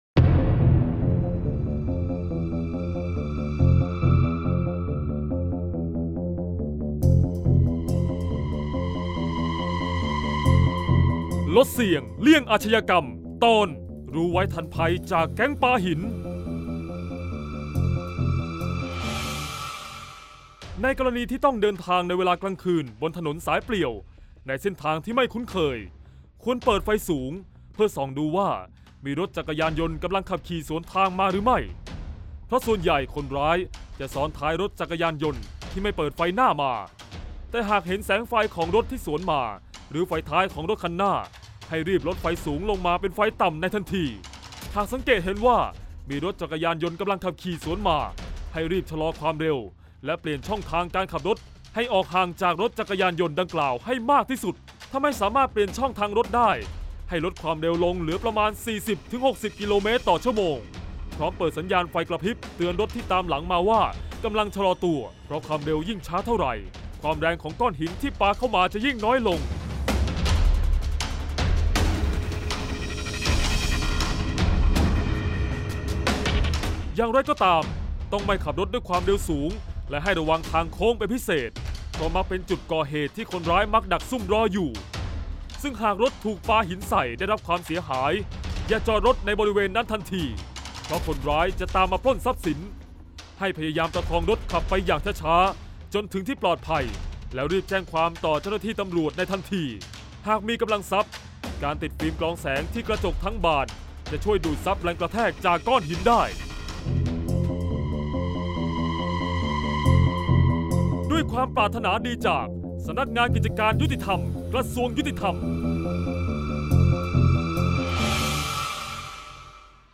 เสียงบรรยาย ลดเสี่ยงเลี่ยงอาชญากรรม 49-ระวังแก๊งปาหิน